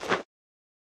equip_generic2.ogg